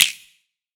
BWB THE WAVE SNAP (15).wav